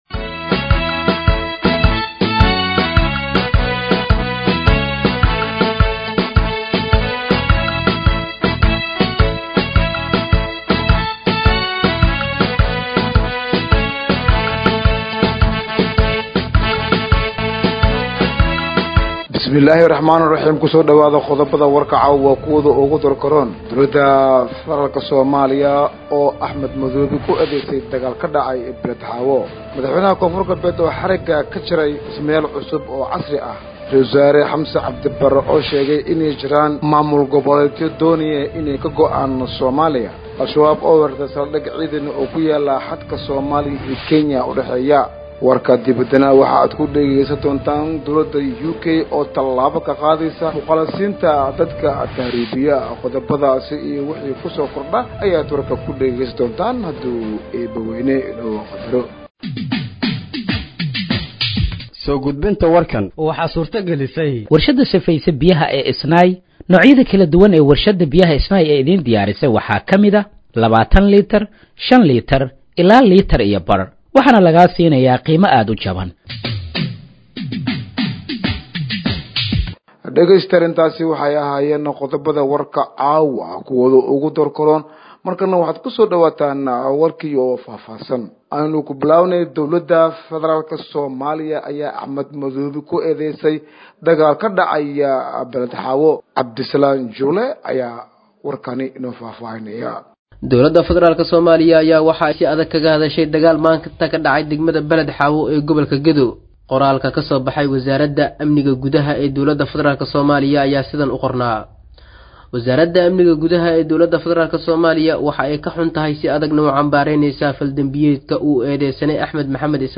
Dhageeyso Warka Habeenimo ee Radiojowhar 22/07/2025